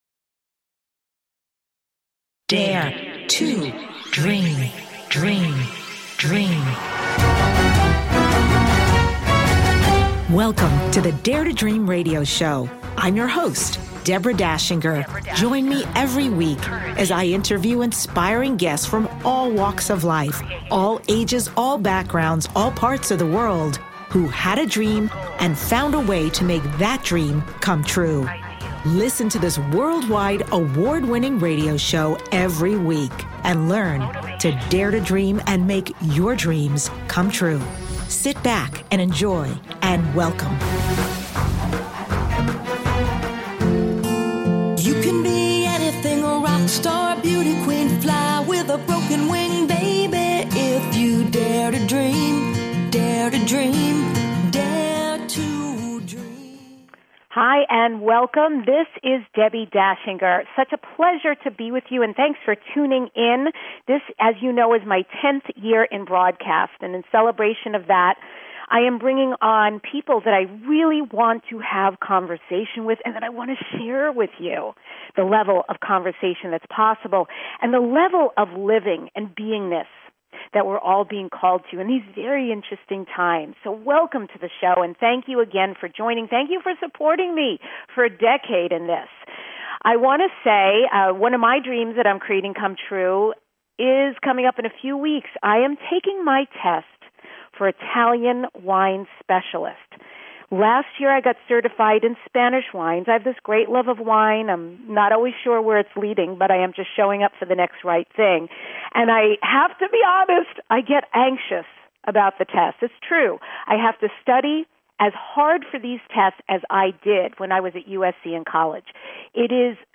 Guest, JAMES VAN PRAAGH